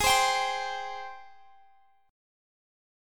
AbM7 Chord
Listen to AbM7 strummed